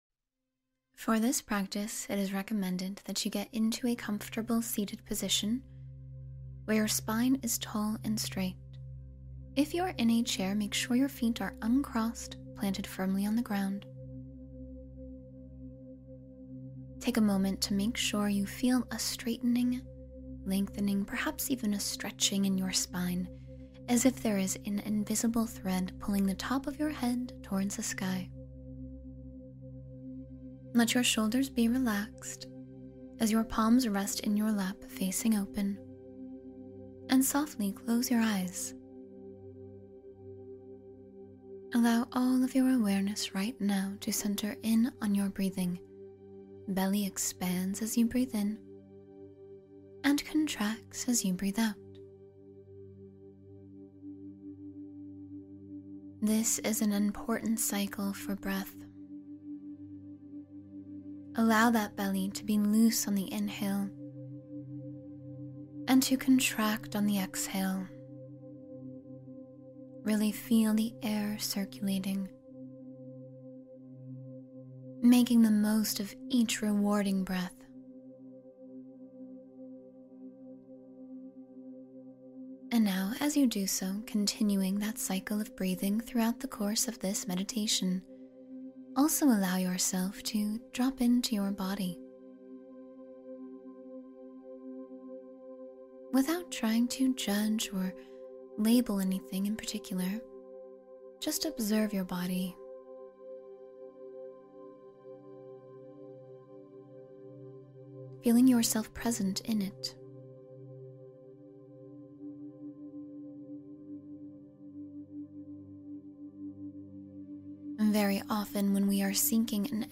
Boost Energy and Motivation in Just 10 Minutes — Morning Meditation